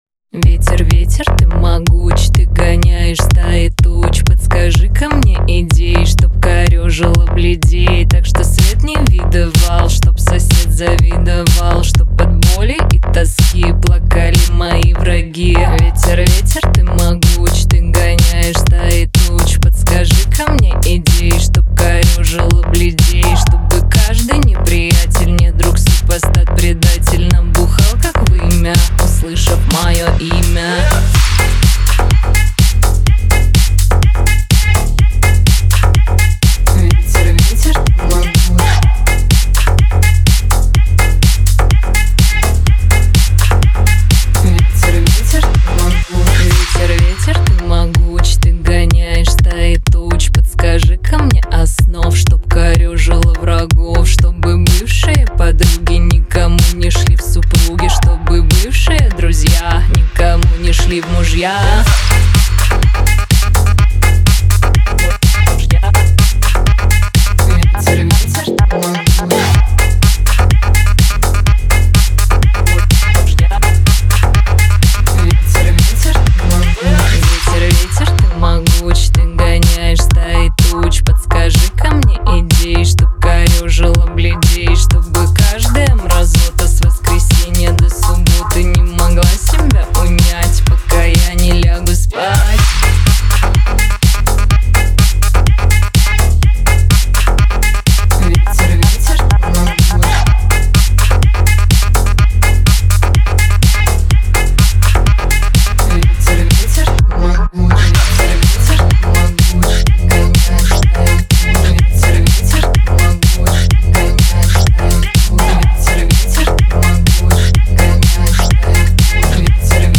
• Качество MP3: 320 kbps, Stereo